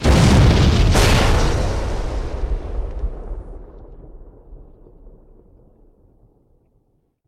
otherdestroyed4.ogg